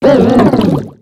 Cri de Moyade dans Pokémon X et Y.